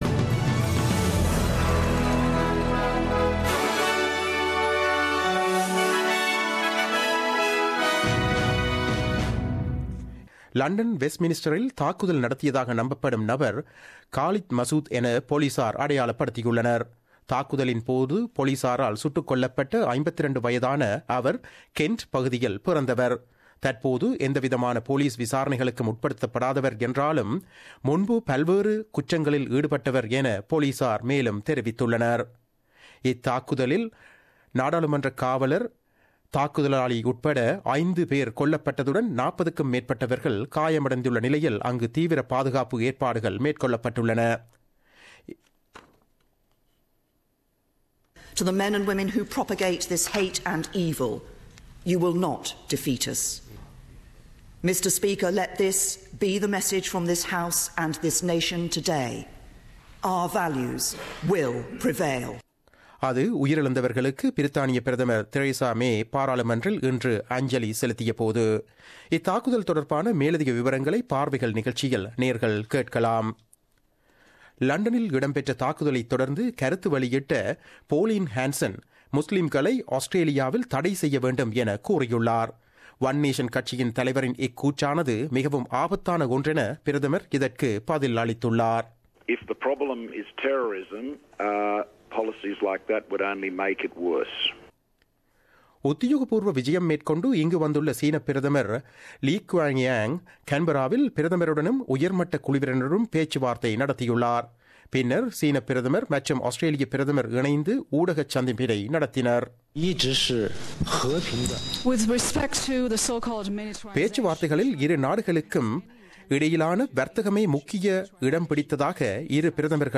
The news bulletin aired on Friday 24 March 2017 at 8pm.